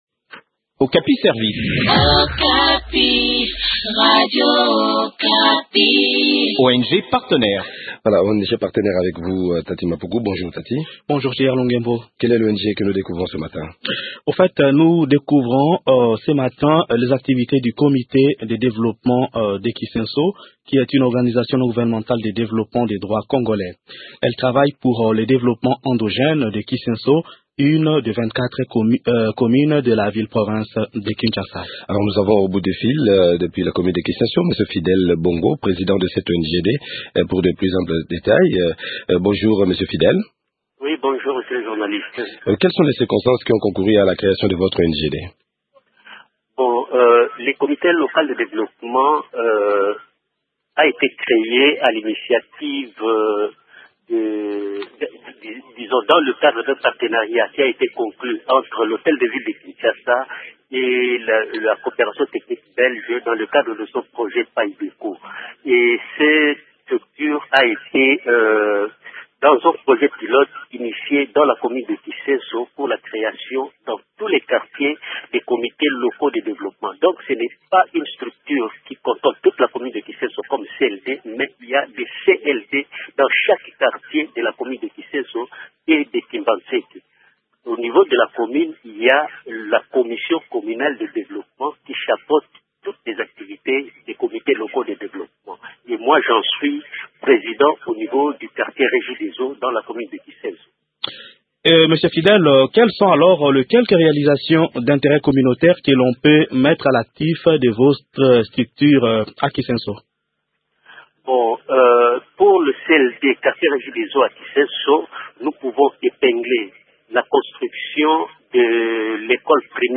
Le projet de lutte antiérosive figure aussi parmi les activités principales de CDK. Découvrez les activités de cette ONGD dans cet entretien